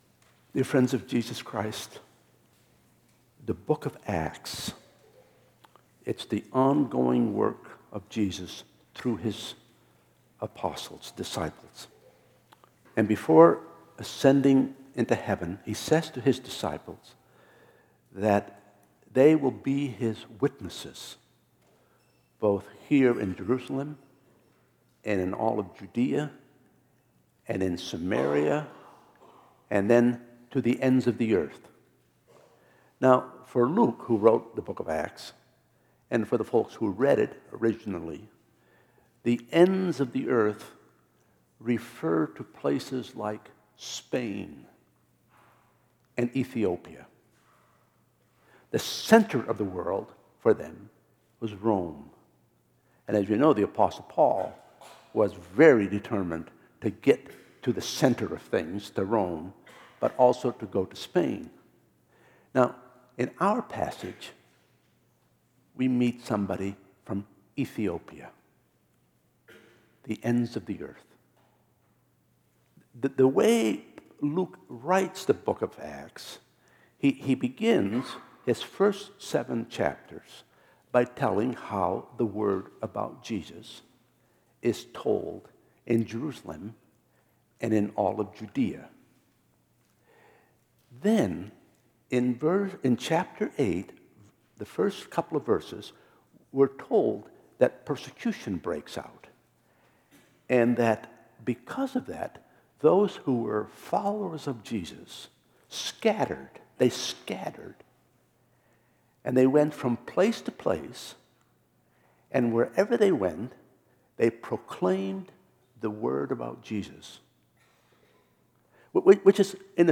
2015 Sermons The Man from Ethiopia Play Episode Pause Episode Mute/Unmute Episode Rewind 10 Seconds 1x Fast Forward 30 seconds 00:00 / Subscribe Share RSS Feed Share Link Embed Download file | Play in new window